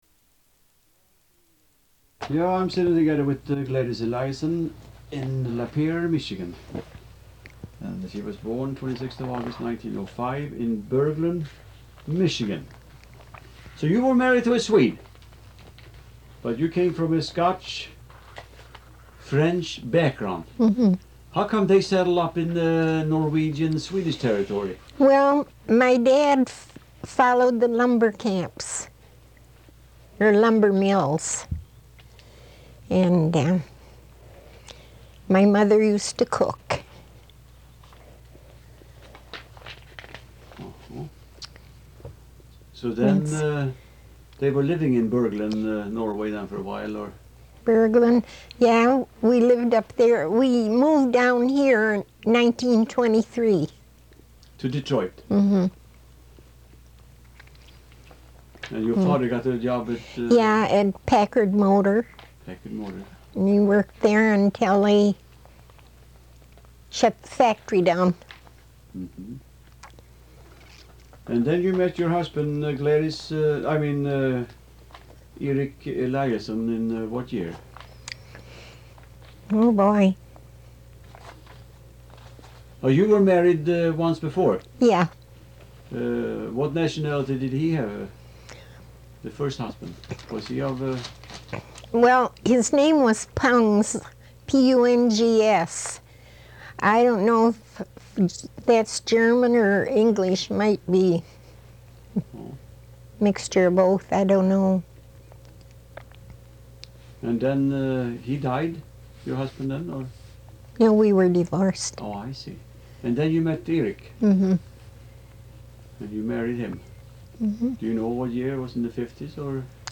Intervju.